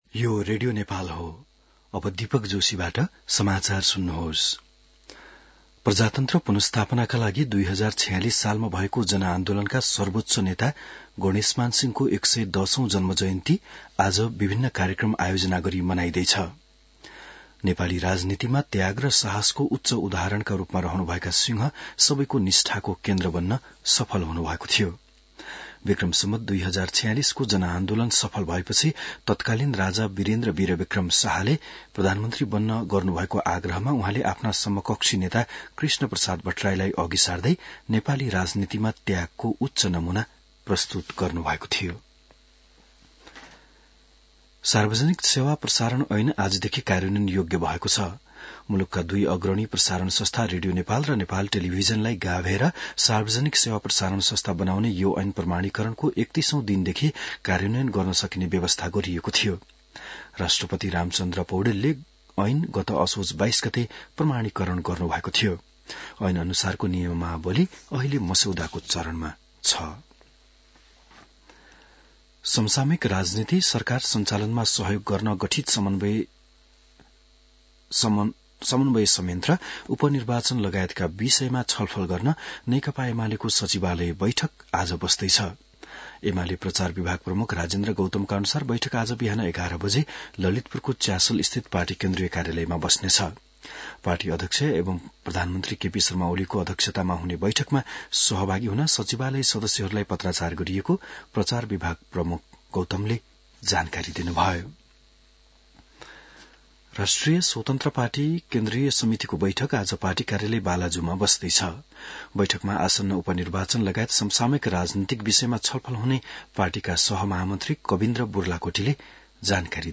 बिहान १० बजेको नेपाली समाचार : २५ कार्तिक , २०८१